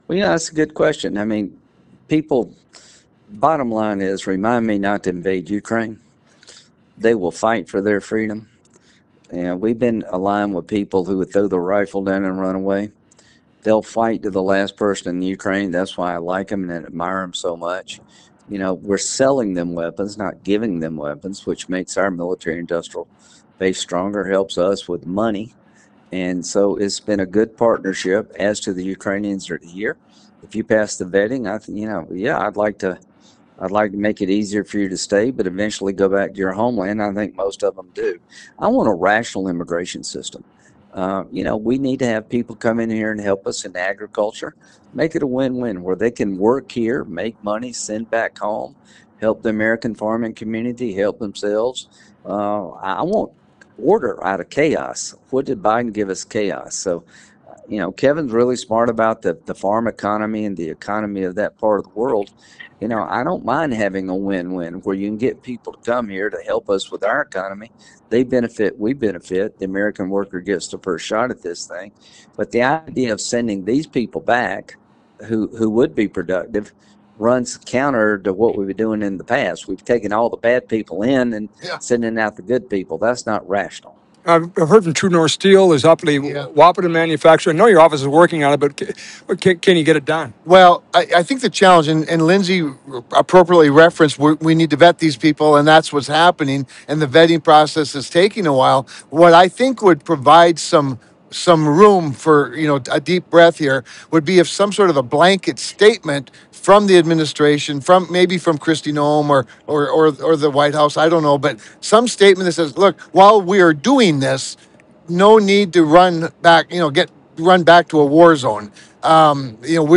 Listen:  Senators Kevin Cramer (R-ND) and Lindsey Graham (R-SC) on ‘What’s On Your Mind?’